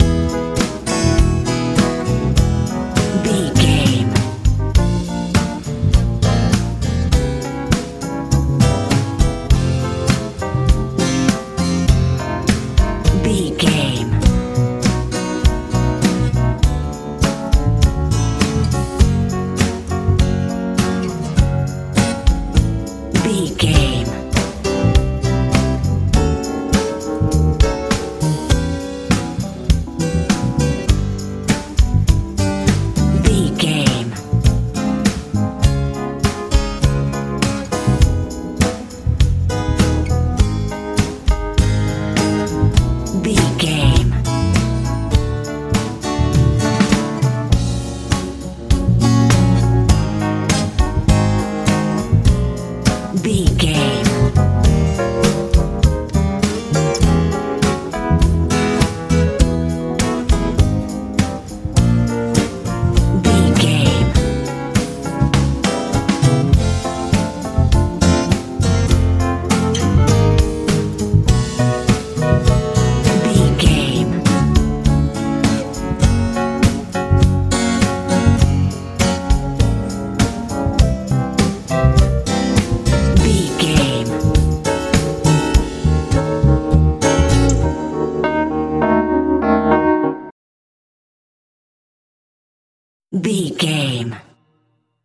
lite pop
Ionian/Major
soft
light
organ
acoustic guitar
bass guitar
drums
70s
80s
uplifting
lively
hopeful